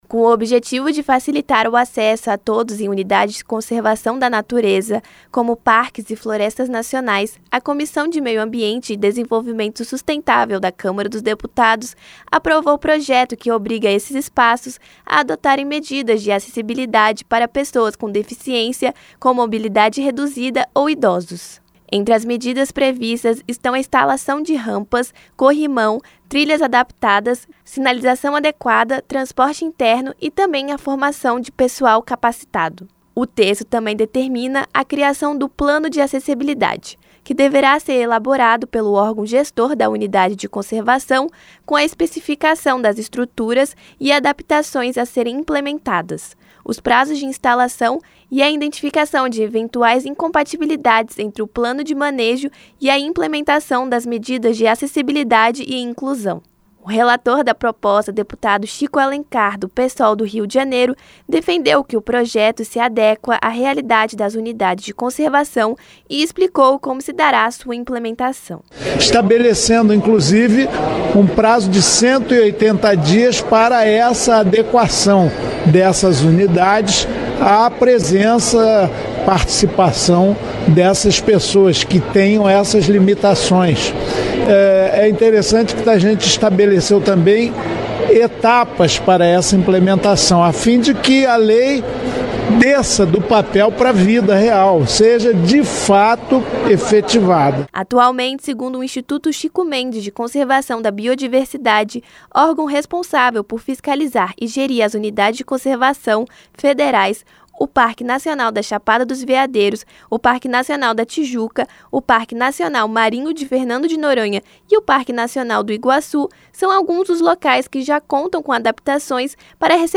COMISSÕES DA CÂMARA ANALISAM PROJETO QUE OBRIGA UNIDADES DE CONSERVAÇÃO A GARANTIREM O ACESSO PARA PESSOAS COM DEFICIÊNCIA, COM MOBILIDADE REDUZIDA OU IDOSOS. QUEM TRAZ OS DETALHES É A REPÓRTER